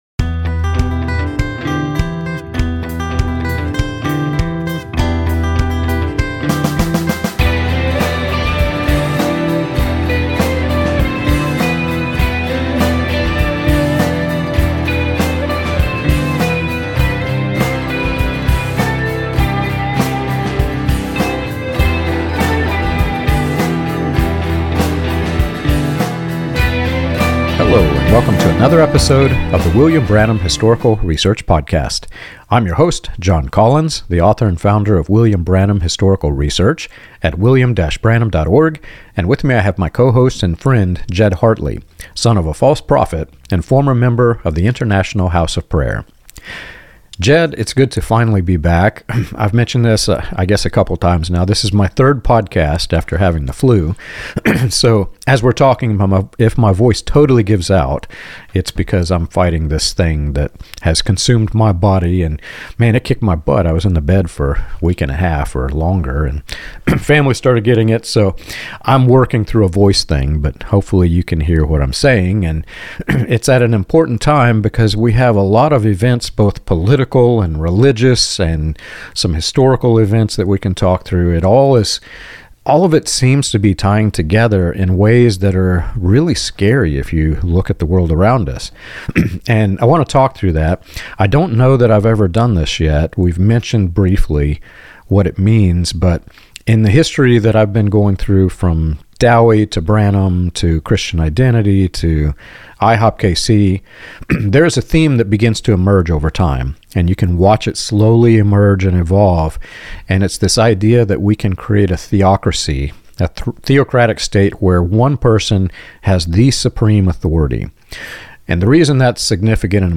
The conversation traces the ideological lineage from early healing revivals and Branhamism to the New Apostolic Reformation, showing how spiritual language is used to shield power, suppress dissent, and bypass accountability.